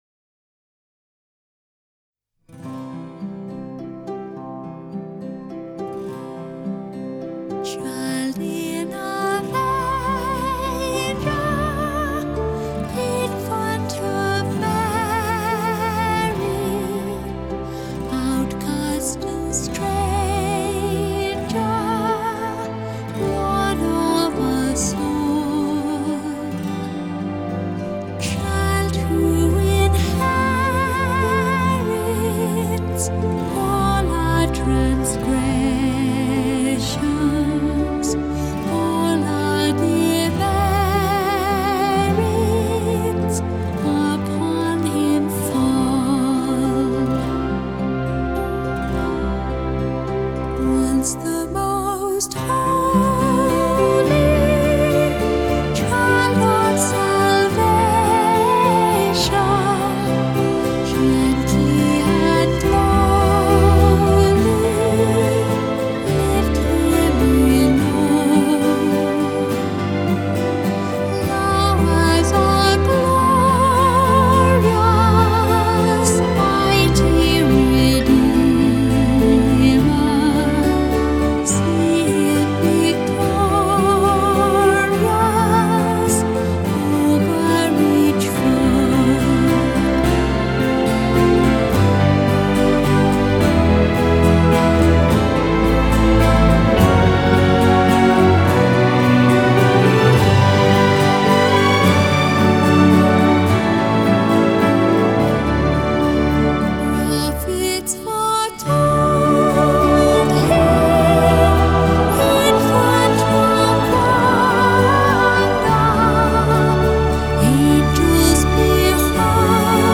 Genre: Crossover